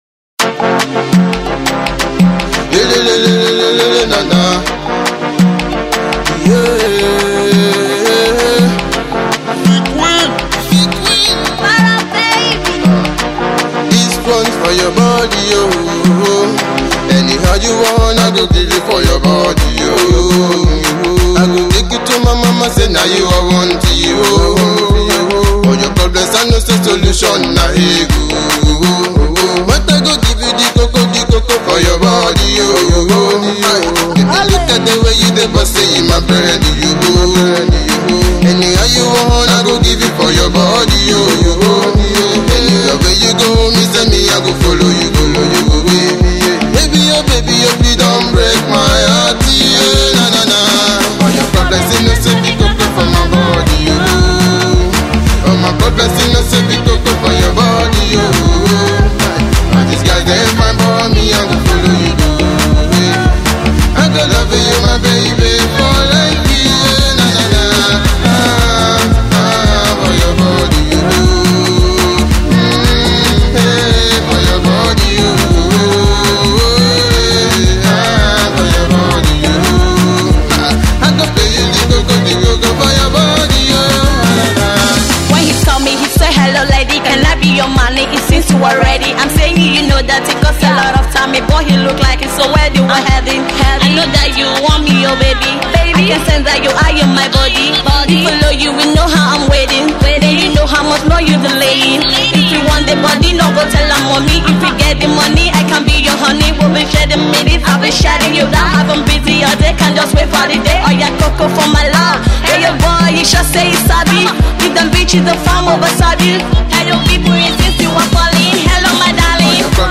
high-life tune